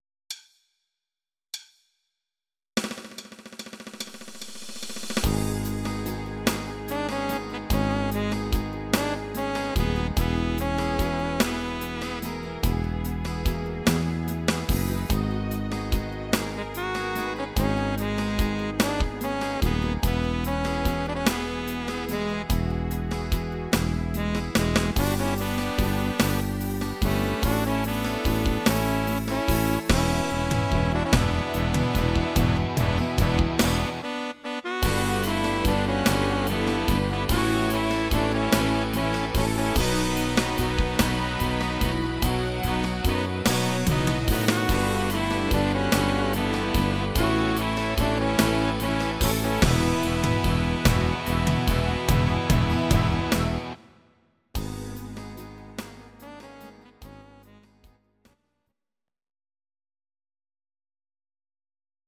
Audio Recordings based on Midi-files
Rock, Musical/Film/TV, 2000s